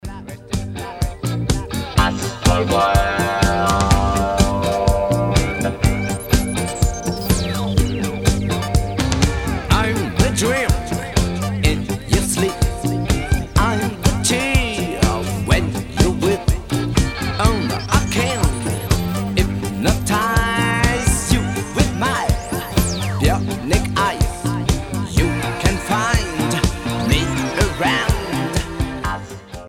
facevano uso massiccio di elettronica e sintetizzatori